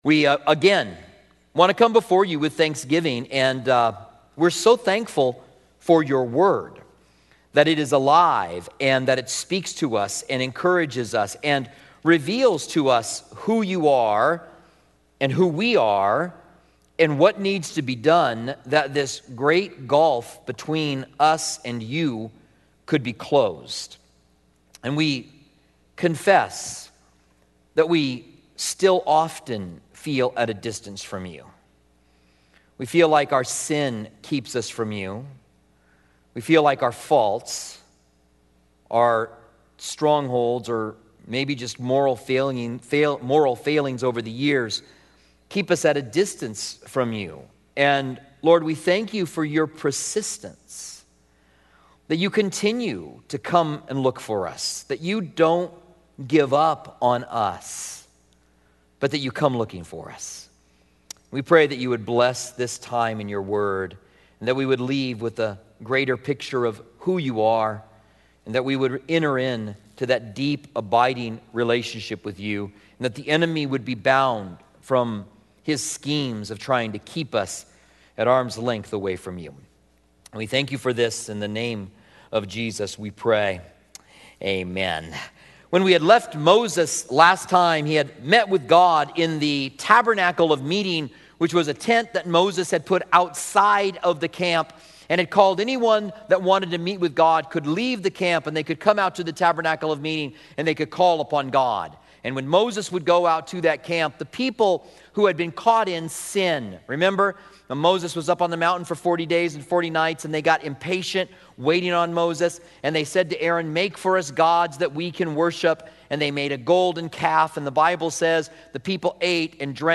Commentary on Exodus